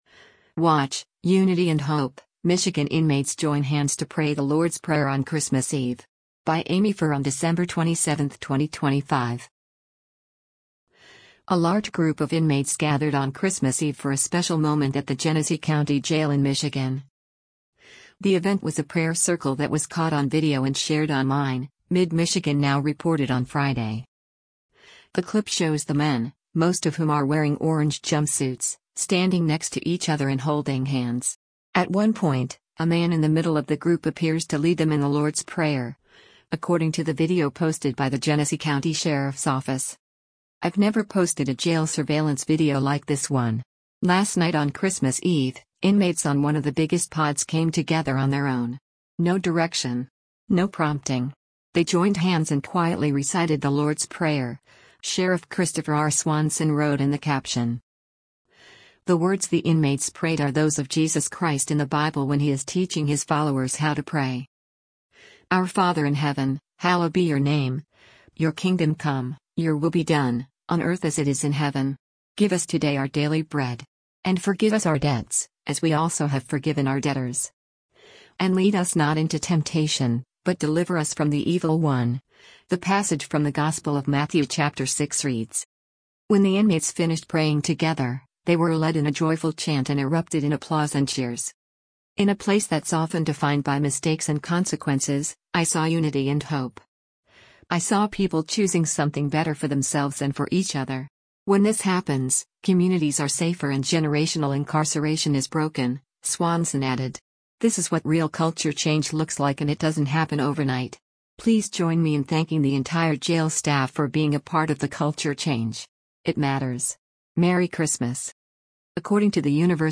A large group of inmates gathered on Christmas Eve for a special moment at the Genesee County Jail in Michigan.
At one point, a man in the middle of the group appears to lead them in the Lord’s Prayer, according to the video posted by the Genesee County Sheriff’s Office.
When the inmates finished praying together, they were led in a joyful chant and erupted in applause and cheers.